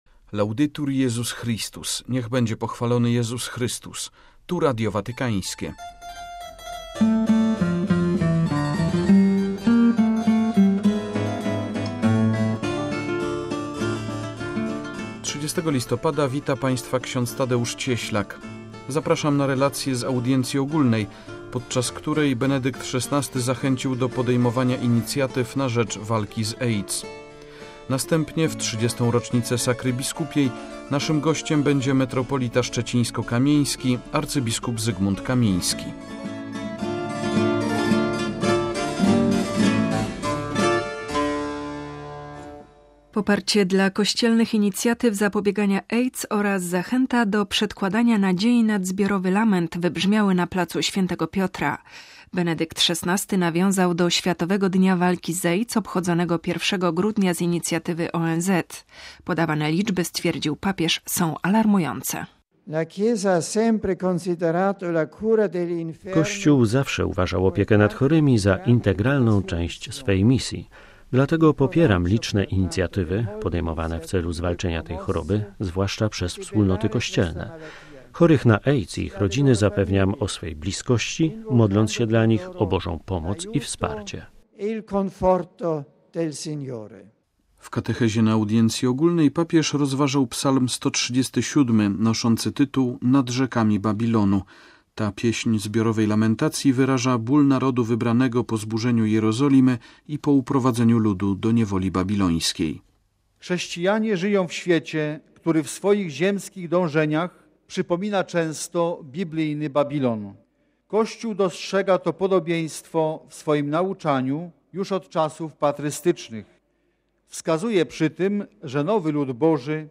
Relacja z audiencji ogólnej, podczas której Benedykt XVI zachęcił do podejmowania inicjatyw na rzecz walki z AIDS; W 30. rocznicę sakry biskupiej naszym gościem jest metropolita szczecińsko-kamieński, abp Zygmunt Kamiński.